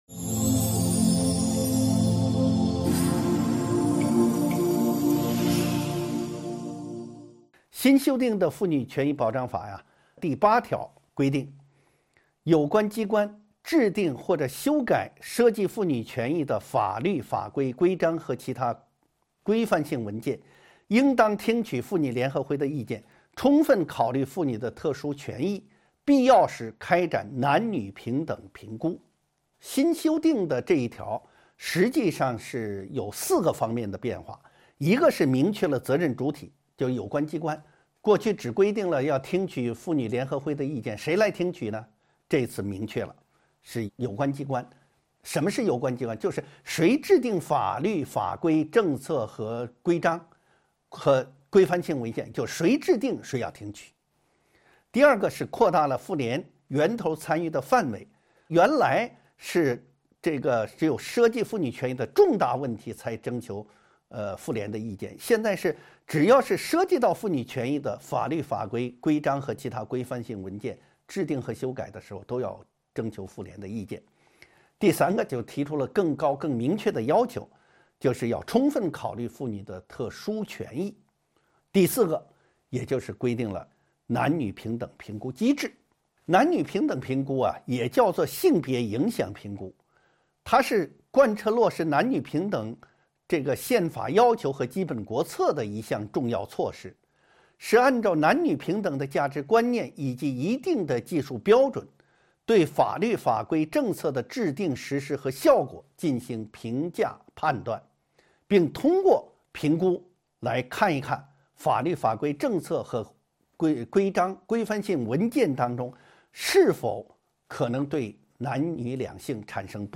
音频微课：《中华人民共和国妇女权益保障法》10.男女平等评估制度